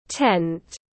Cái lều tiếng anh gọi là tent, phiên âm tiếng anh đọc là /tent/.
Tent /tent/